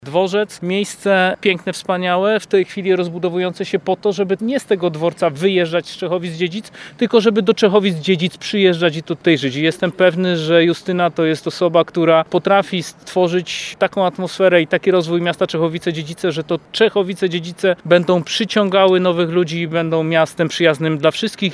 Jej kandydaturę oficjalnie ogłosił na briefingu prasowym, który odbył się na dworcu kolejowym w tym mieście w Dniu Kobiet, były minister, a obecnie poseł Grzegorz Puda.
Kandydatkę na burmistrza Czechowic-Dziedzic przyjechali wesprzeć posłowie PiS.